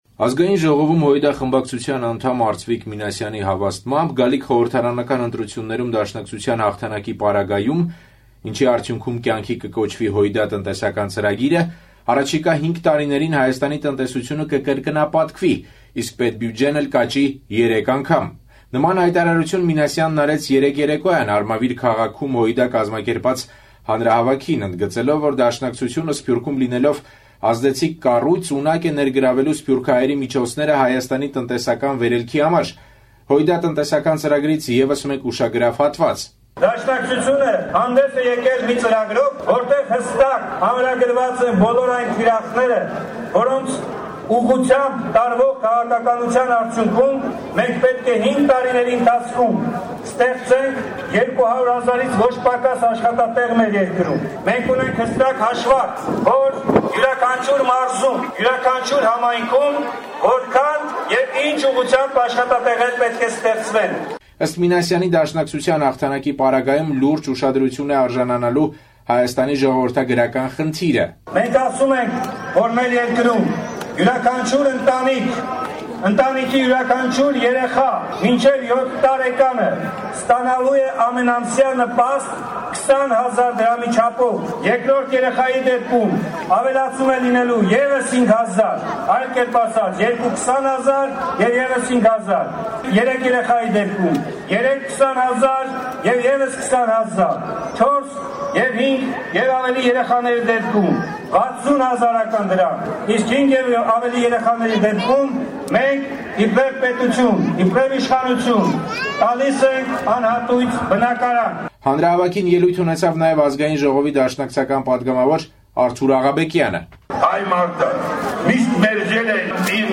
Կուսակցությունը երեքշաբթի օրը նախընտրական հանրահավաք անցկացրեց Արմավիրում։